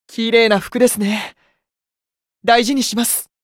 觉醒语音 綺麗な服ですね。